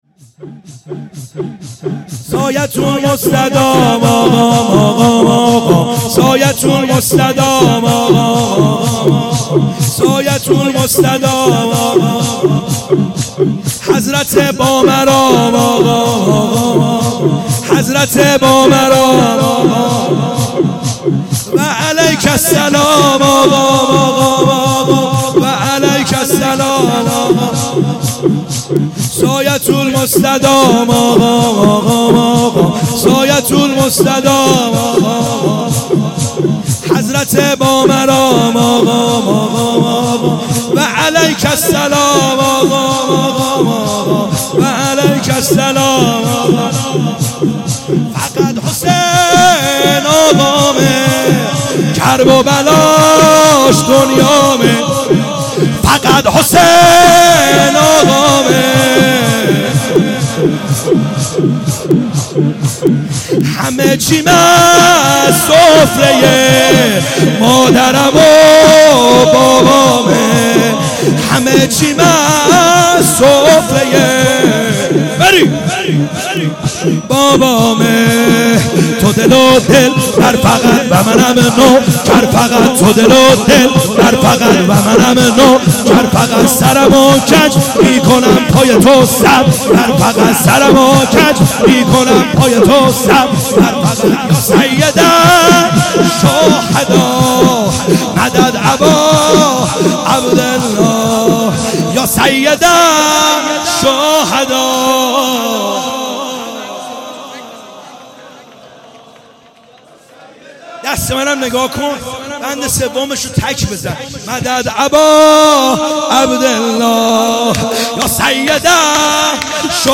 خیمه گاه - بیرق معظم محبین حضرت صاحب الزمان(عج) - شور | سایتون مستدام آقام آقام